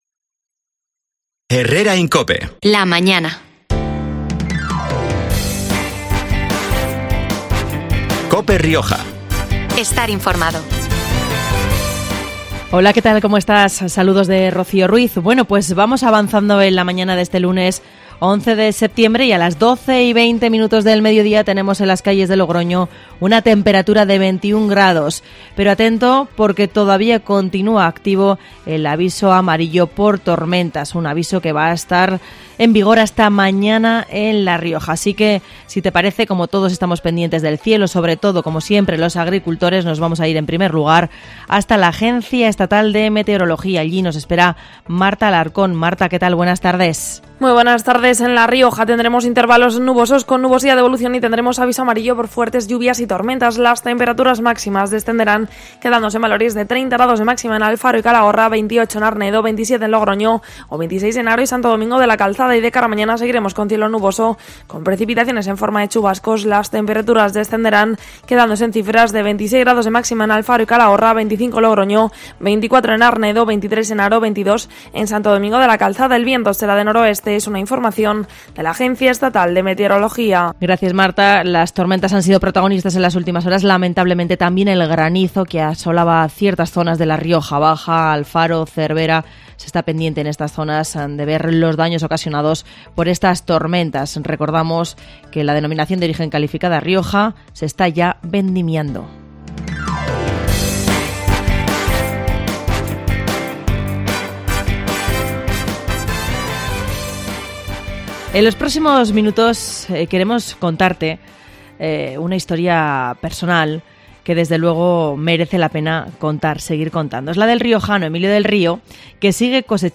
Emilio del Río ha pasado hoy por los micrófonos de COPE Rioja para poner en valor la difusión de las humanidades. Escucha aquí la entrevista al completo.